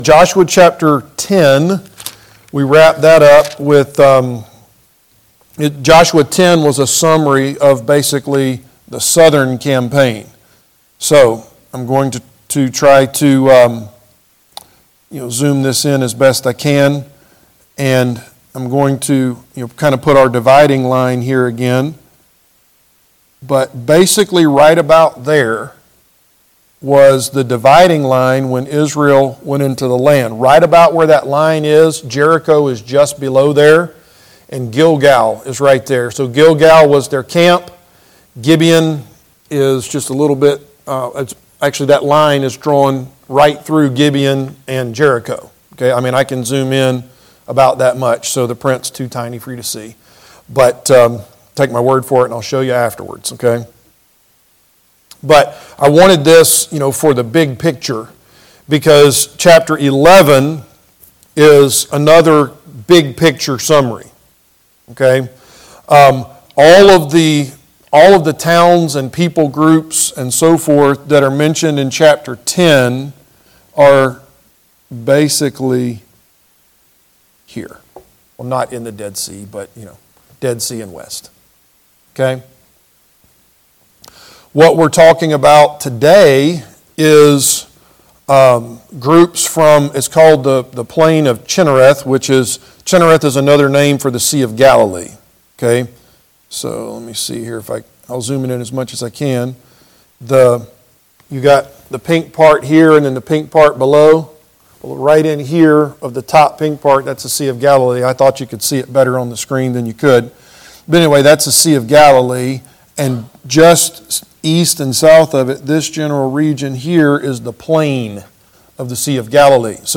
Josh. 11:1- Service Type: Adult Sunday School Class « The Purpose of Church